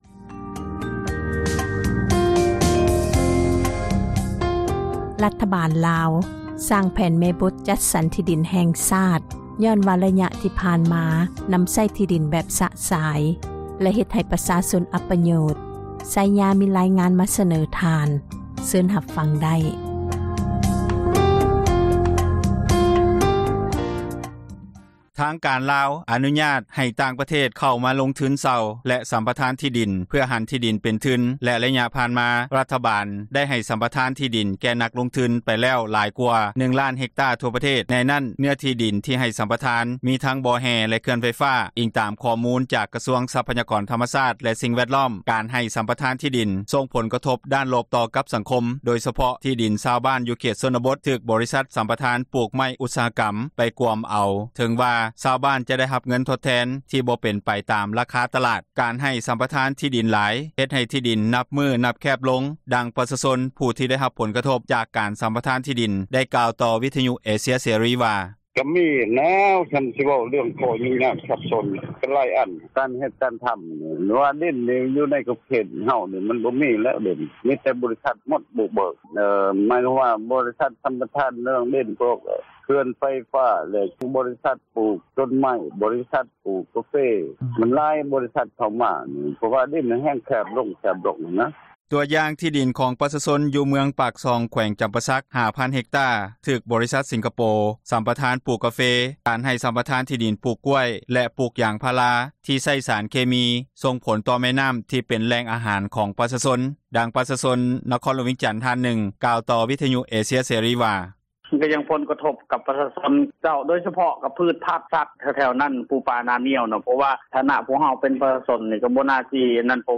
ບັນຫາທີ່ສຳຄັນບໍ່ມີແຜນແມ່ບົດ ການນຳໃຊ້ທີ່ດິນ ເຮັດໃຫ້ມີການຈັບຈອງທີ່ດິນ ແບບຊະຊາຍ, ດັ່ງ ທ່ານ ສົມມາດ ພົນເສນາ ຣັຖມົນຕຼີ ກະຊວງຊັພຍາກອນທຳມະຊາດ ແລະສິ່ງແວດລ້ອມ ໄດ້ກ່າວຢູ່ໃນກອງປະຊຸມສະໄໝສາມັນ ເທື່ອທີ 5 ຂອງສະພາ ແຫ່ງຊາດ ຊຸດທີ 8 ເມື່ອວັນທີ 11 ມີຖຸນາ ທີ່ຜ່ານມາ ມີບາງຕອນທ່ານໄດ່ກ່າວ ໃນກອງປະຊຸມສະພາວ່າ:
ອີງຕາມການຄຸ້ມຄອງທີ່ດິນນັ້ນ ບໍ່ມີການກຳນົດຈະແຈ້ງ, ຊ້ຳຊ້ອນກັນ ແລະບໍ່ເປັນໄປຕາມຂັ້ນຕອນ, ດັ່ງສະມາຊິກ ສະພາແຫ່ງຊາຕ ແຂວງສວັນນະເຂດ ໄດ້ກ່າວວ່າ: